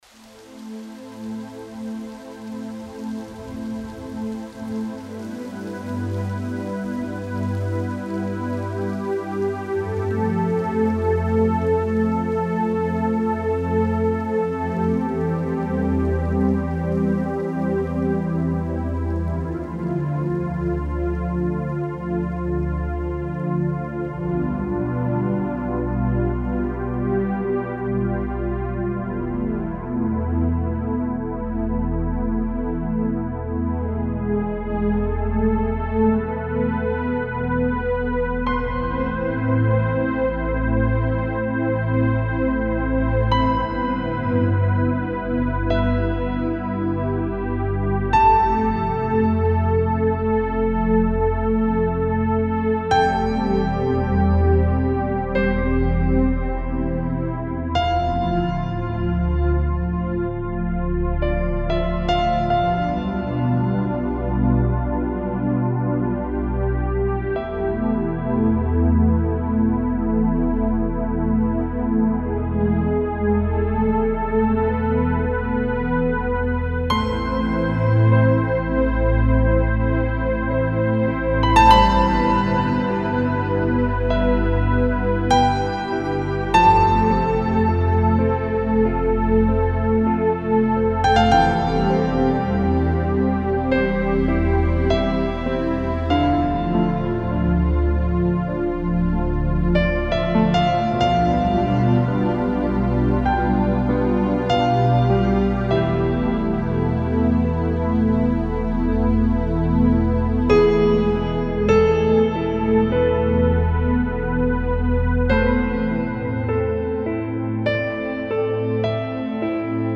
موسیقی بی کلام ملل